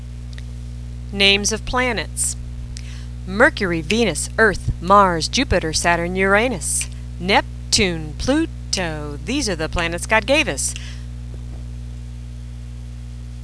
Ditty Names of Planets